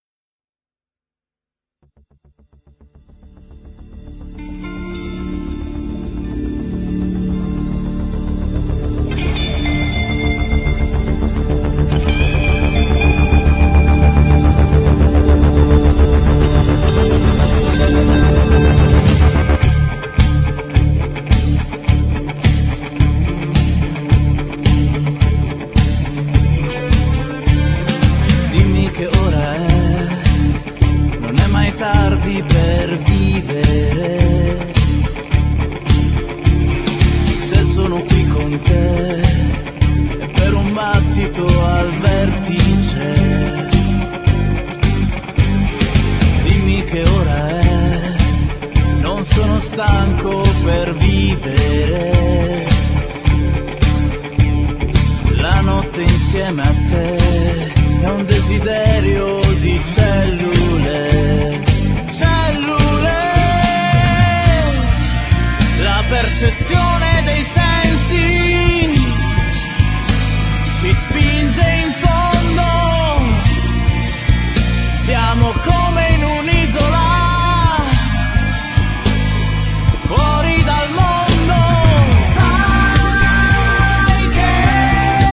Genere : Pop/Rock